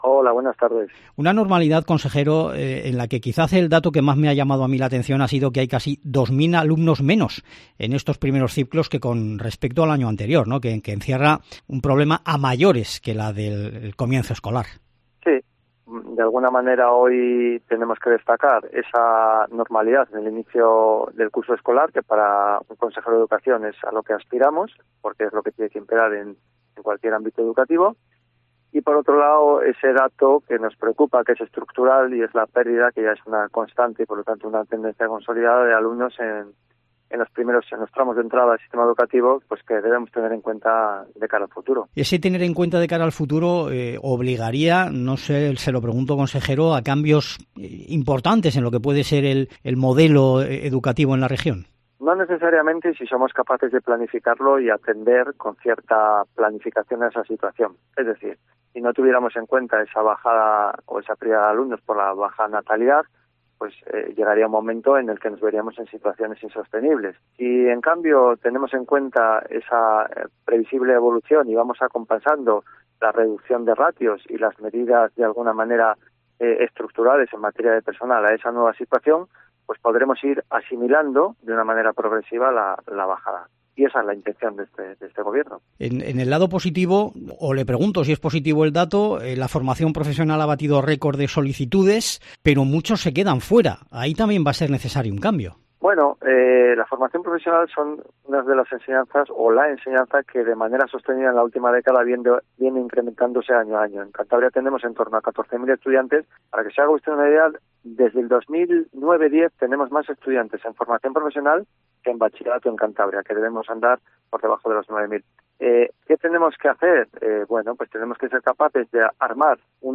Sergio Silva, consejero de educación en Mediodía COPE